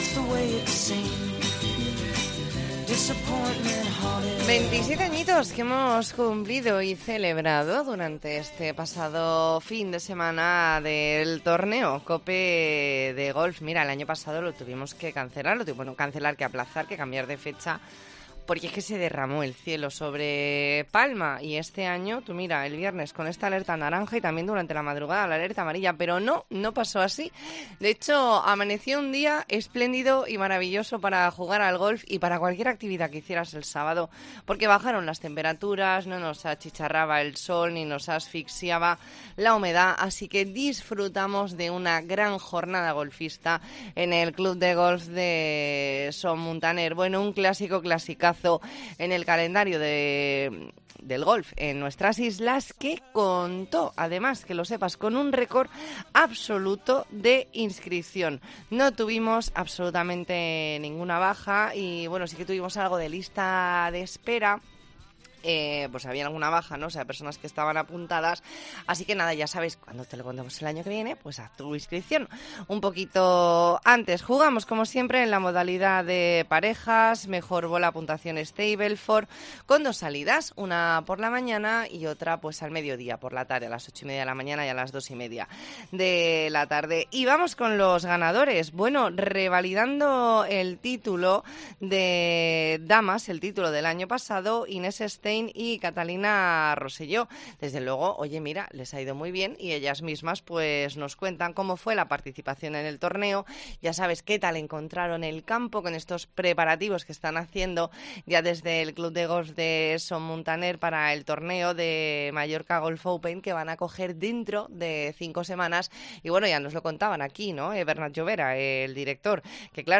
Reportaje con las parejas ganadoras de la XVII edición del Torneo COPE de Golf celebrado en Son Muntaner el 17 de septiembre de 2022